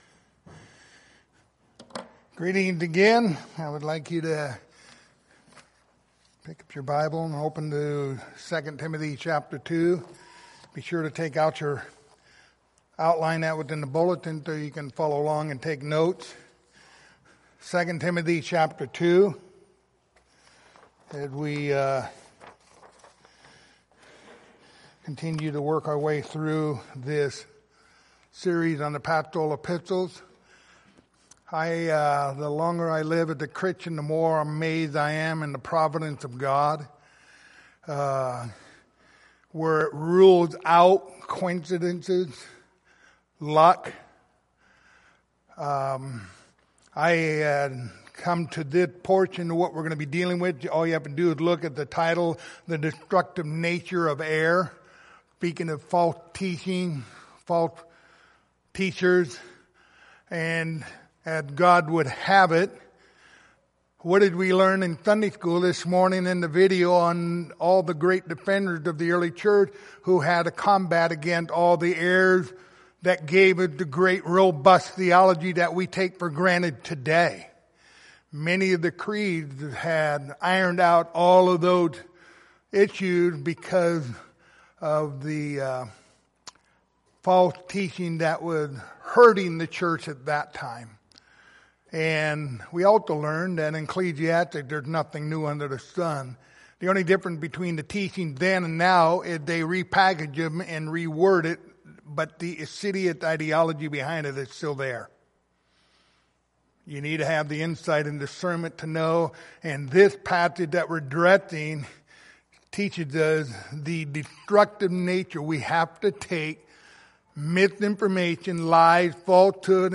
Passage: 2 Timothy 2:16-18 Service Type: Sunday Morning